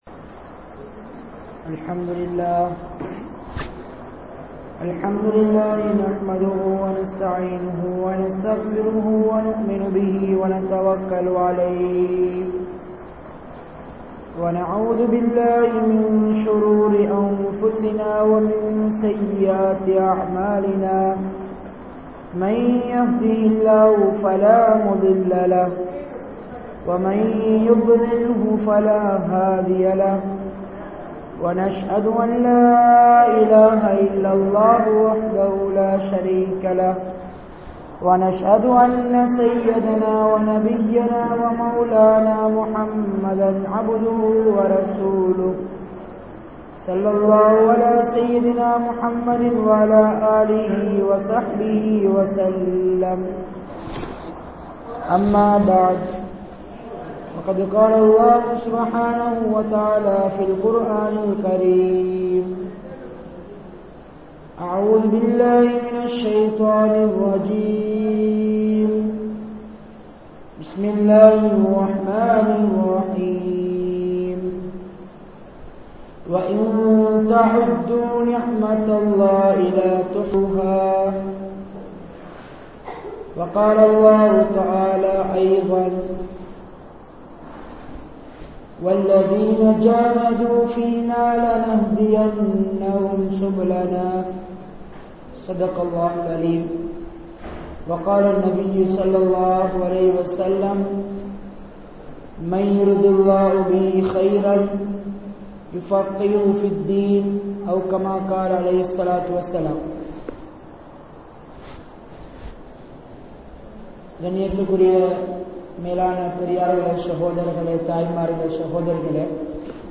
Allah`vin Niumath (அல்லாஹ்வின் நிஃமத்) | Audio Bayans | All Ceylon Muslim Youth Community | Addalaichenai